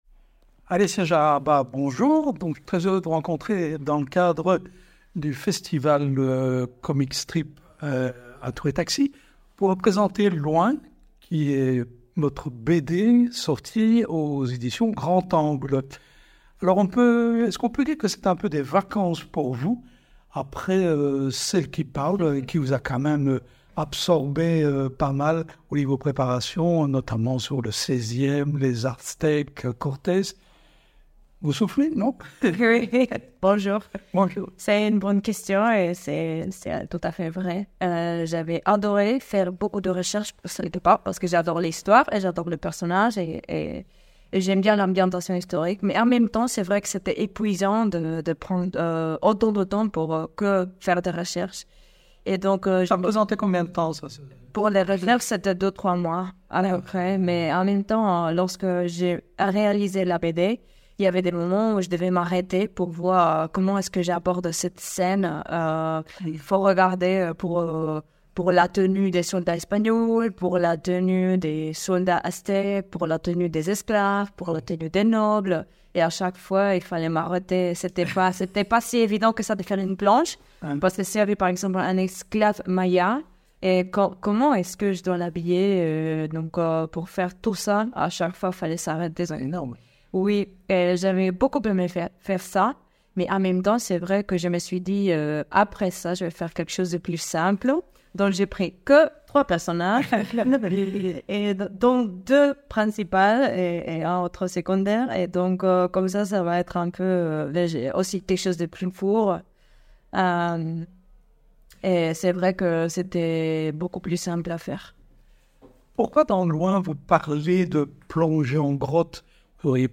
Pourquoi pas un road trip direction Cabo de Gata, paradis des plongeurs? « LOIN», une BD qui parle (très bien) de la peur de sortir de sa zone de confort et de l’inertie d’une relation de couple qui ne sait plus où elle va… Rencontre.